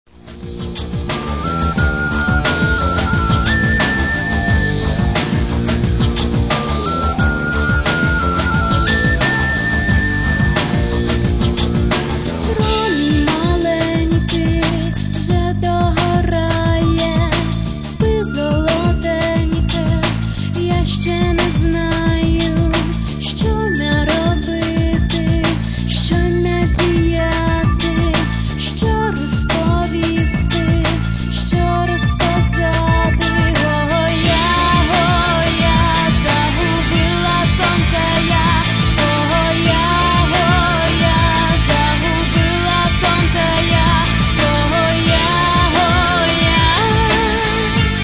Catalogue -> Rock & Alternative -> Compilations